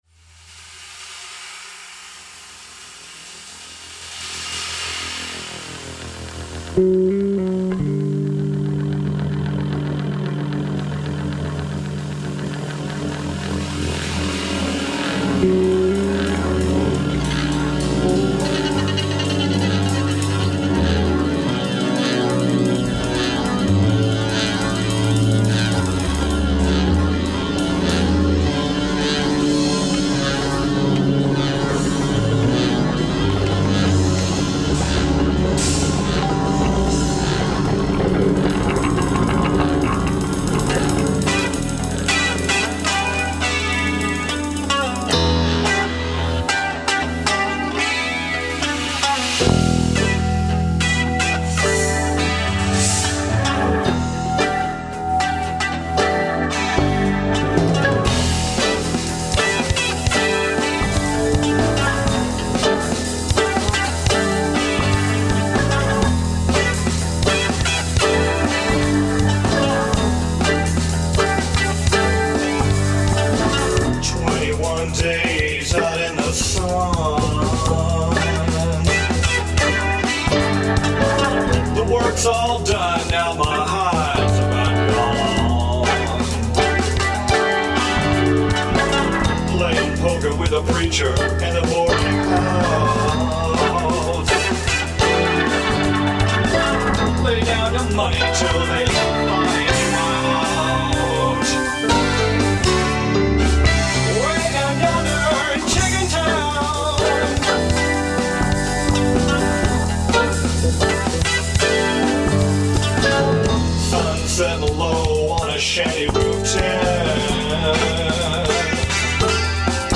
open up a four barrel of V-8 ROCK~A~BILLY RHYTHM & BLUES.